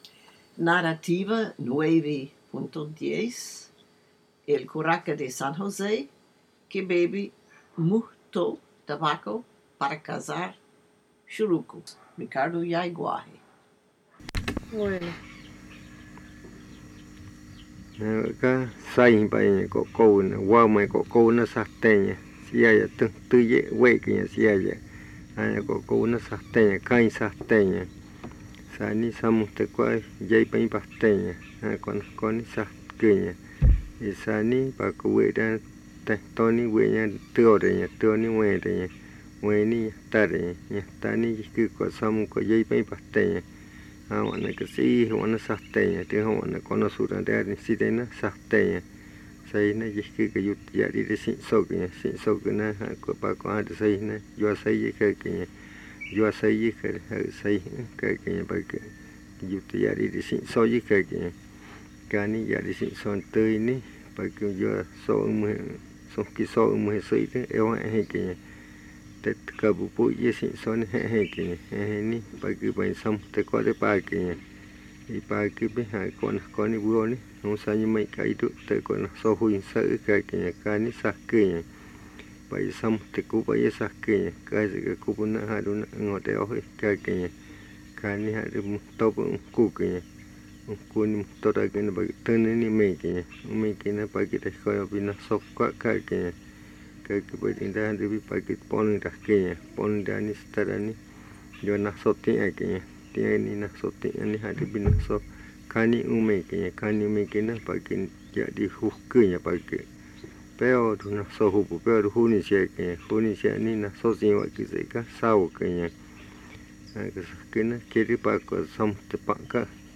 Buenavista, río Putumayo (Colombia)
La narración de un chamán que bebió tabaco para cazar churucos en el río Cuembi. Un chamán de San José bebía tabaco para cazar churucos.